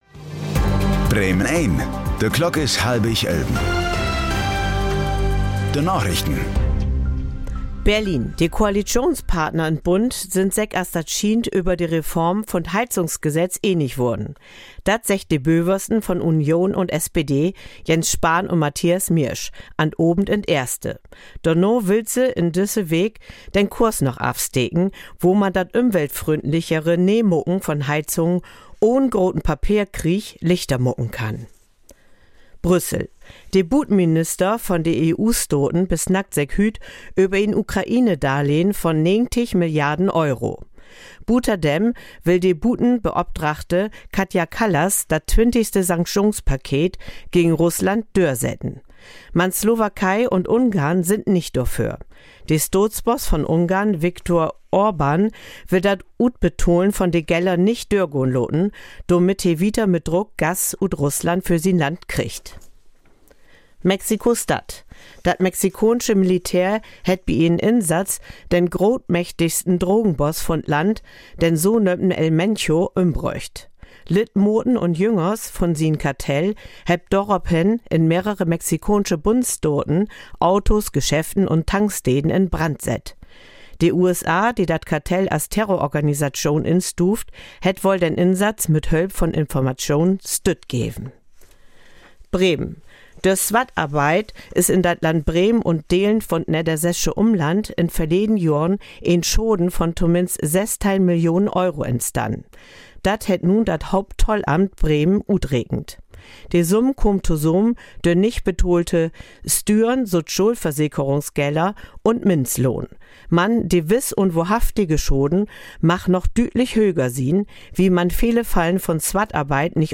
Plattdüütsche Narichten vun'n 23. Februar 2026